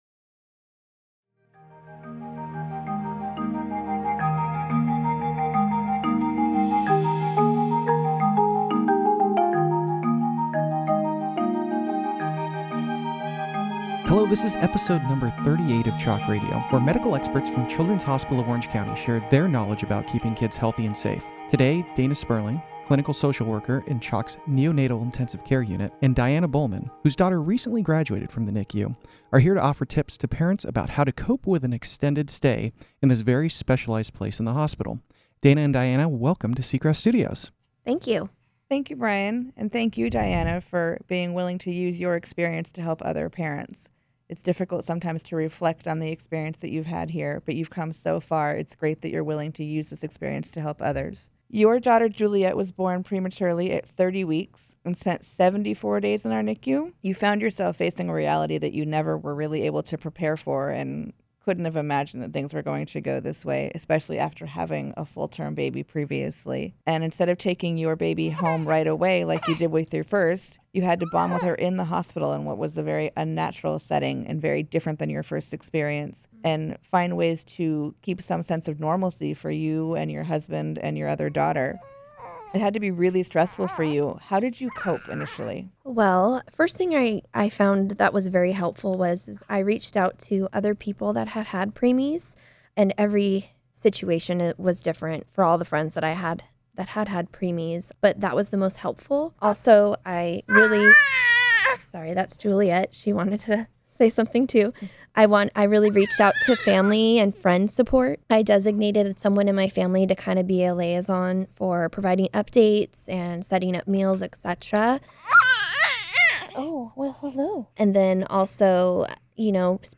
CHOC Radio theme music